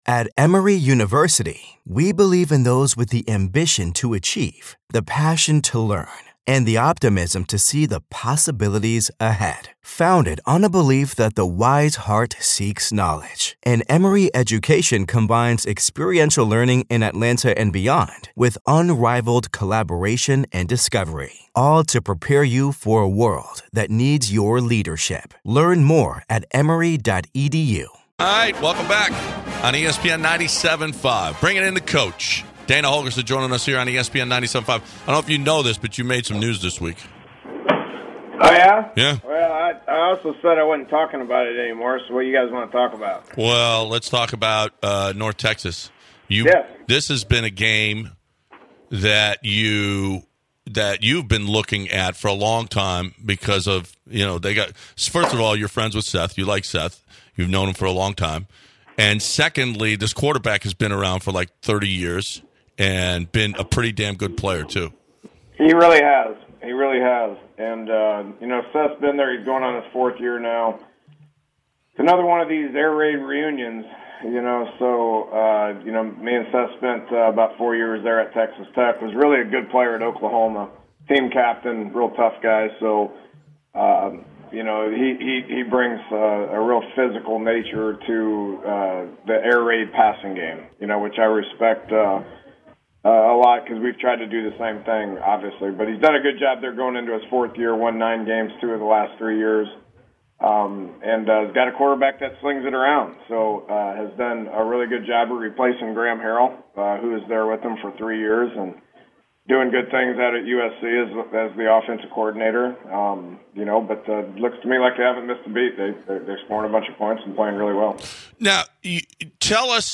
University of Houston's head coach Dana Holgorsen joins the bench.